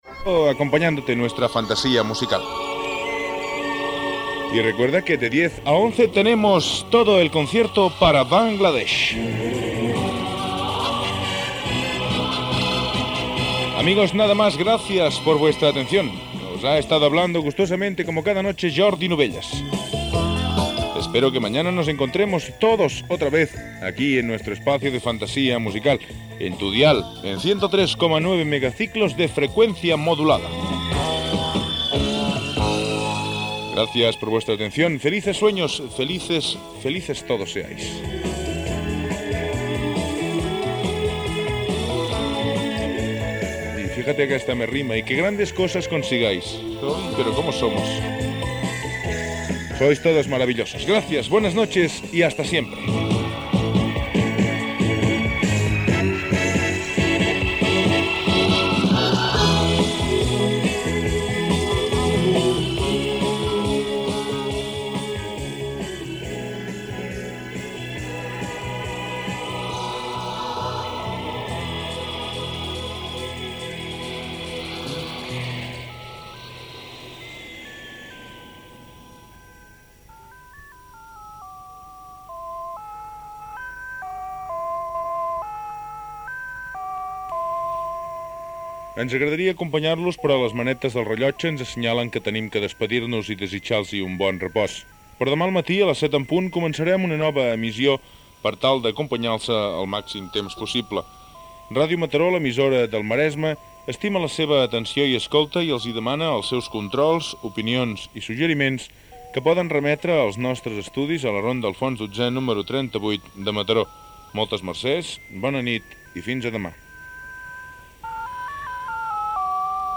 Comiat del programa i tancament de l'emissió amb la sintonia.
Musical